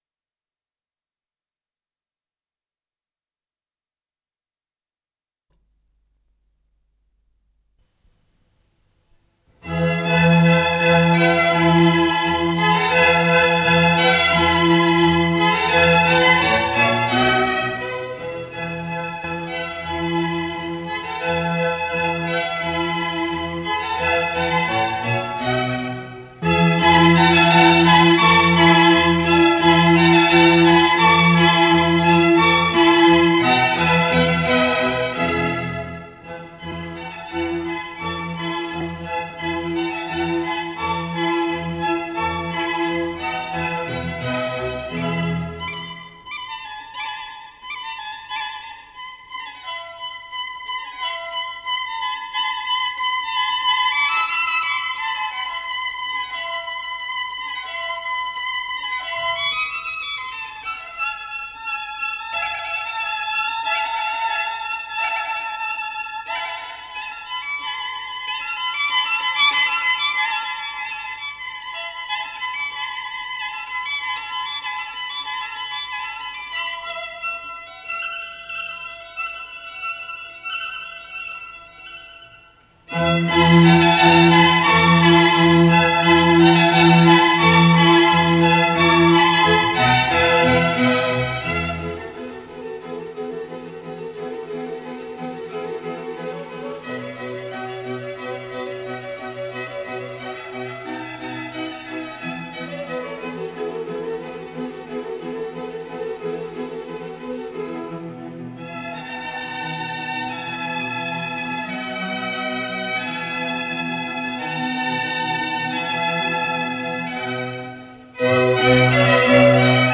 с профессиональной магнитной ленты
ПодзаголовокЦикл, четыре концерта для скрипки с оркестром, концерт №1 - Весна
ИсполнителиКамерный оркестр Ленинградской филармонии
Михаил Вайман - скрипка
ВариантДубль моно